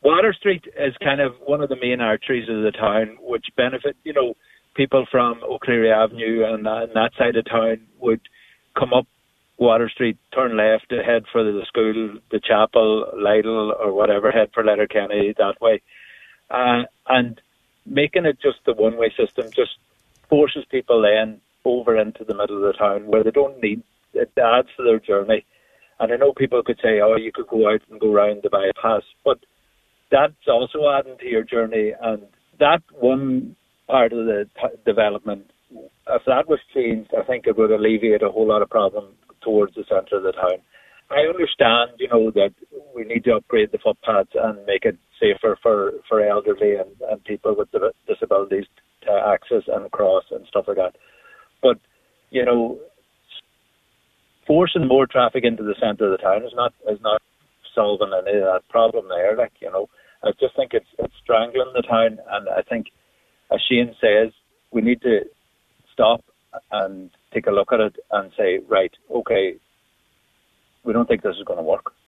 Donegal Town Mayor, Pauric Kennedy: